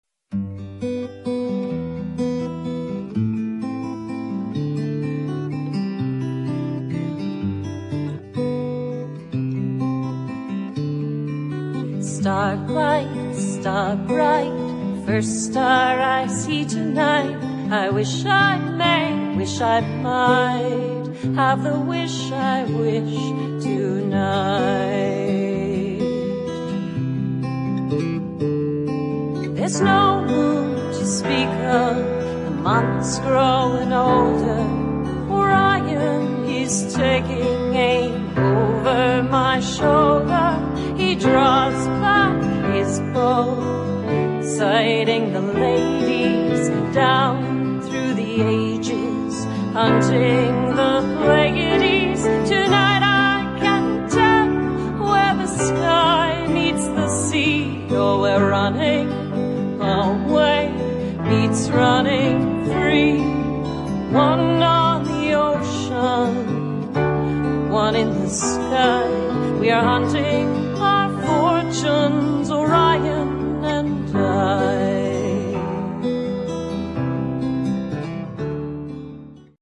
boating music